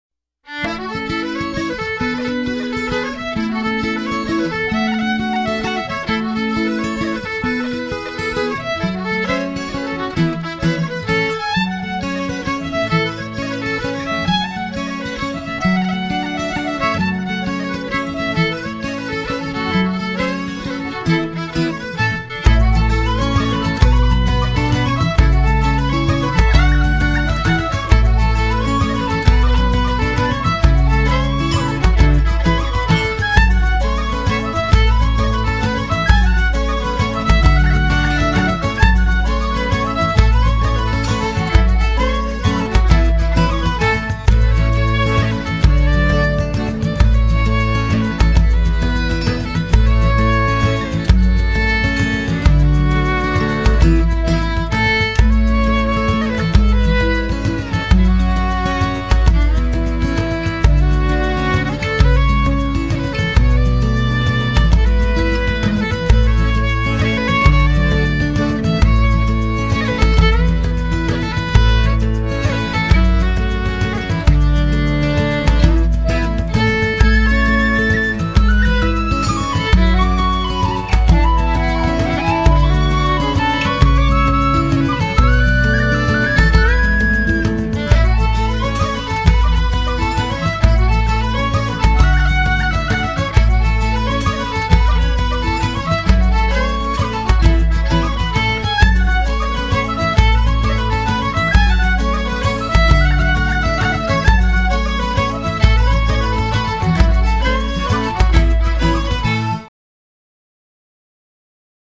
这是我从一个串联的曲子（几支曲子连成一首）中截出来的一段 我觉得非常非常好听 就是好听 其他我说不出来